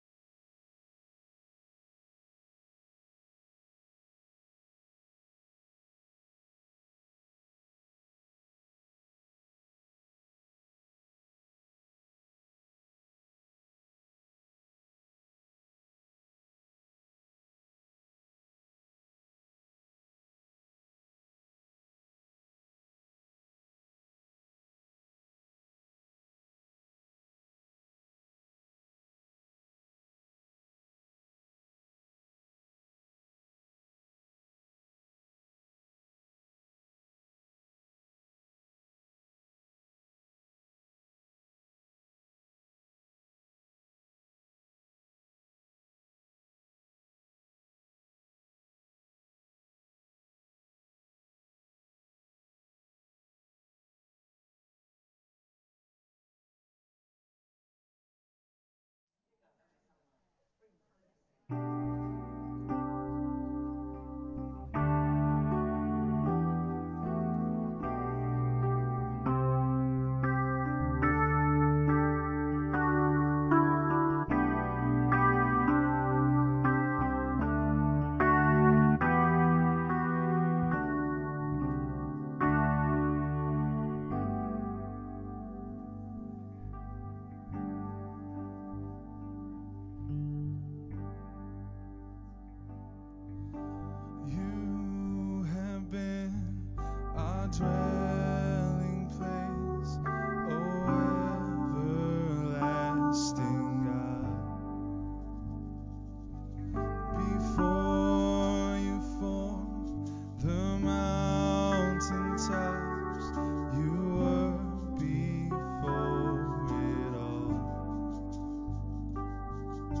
Praise Worship
Psalm reading and exhortation